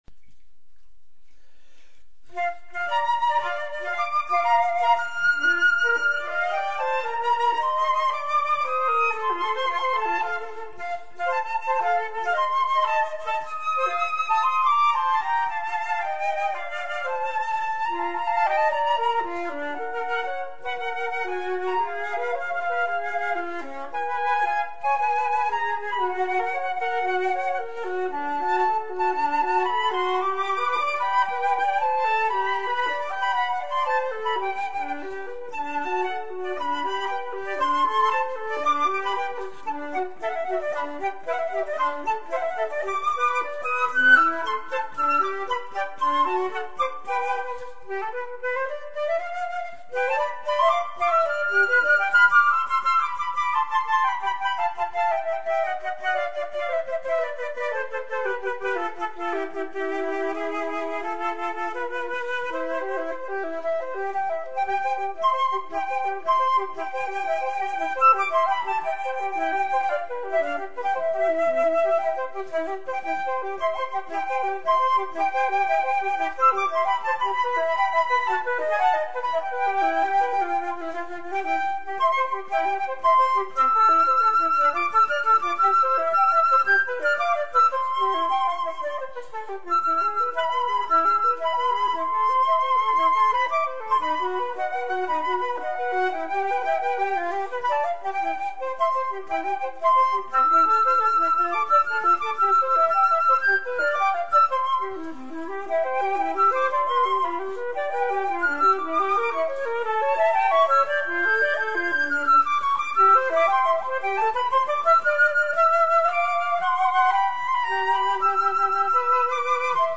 per 2 flauti